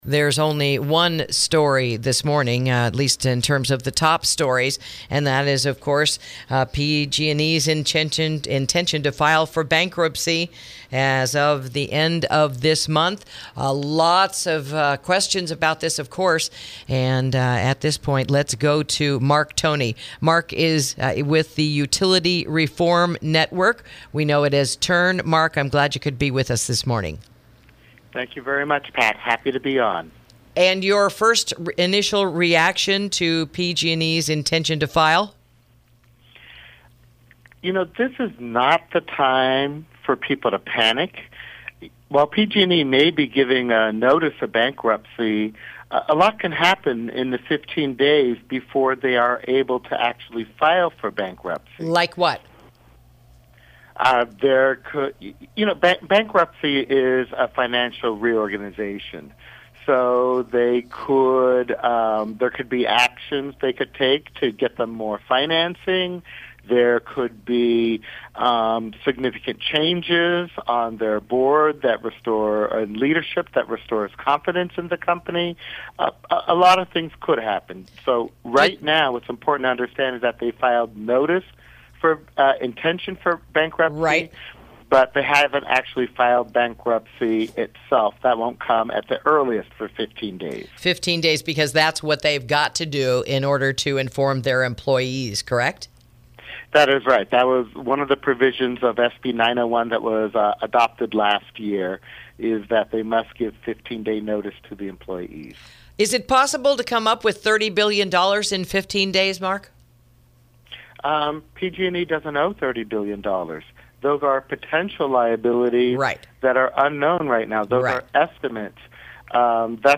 INTERVIEW: An Analysis of the Forthcoming PG&E Bankruptcy